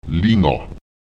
Lautsprecher lena [ÈleNa] das Gewicht (etwas, das ein Gewicht besitzt, kann entweder wác leicht oder pos schwer sein)